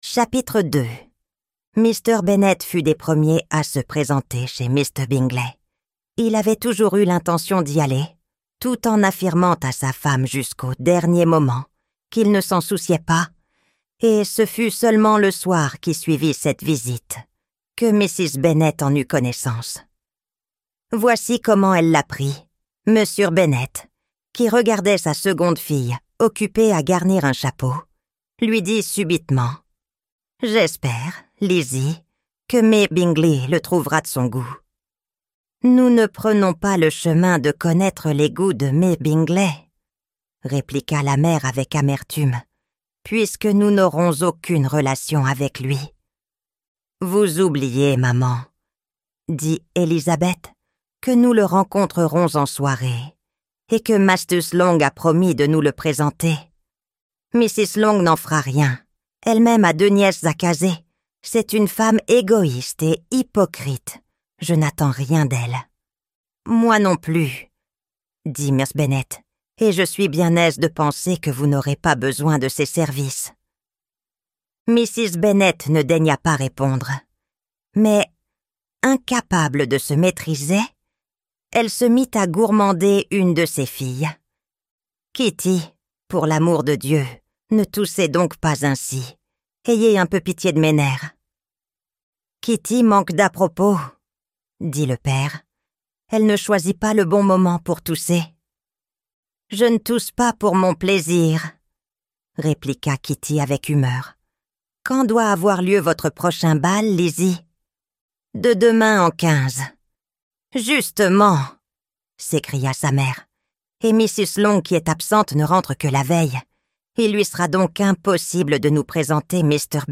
Orgueil et Préjugés - Livre Audio